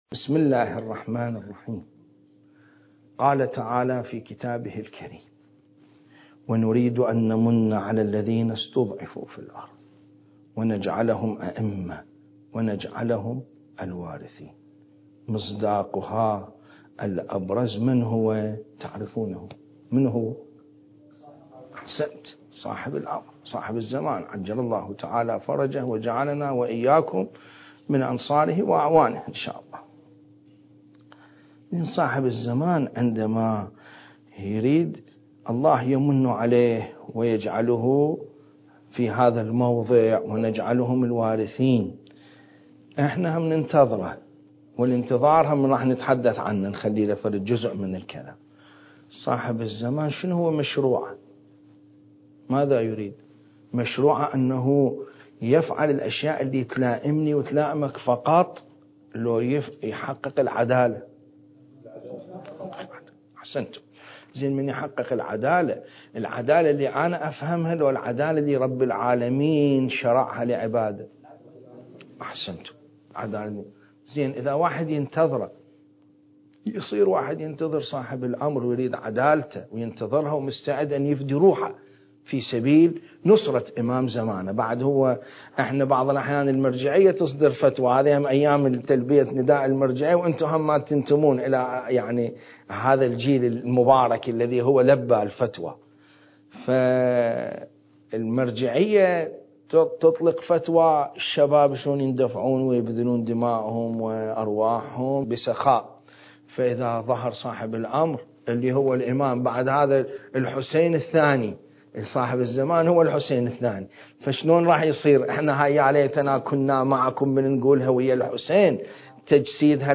المكان: دار العلم/ النجف الأشرف التاريخ: 1446 للهجرة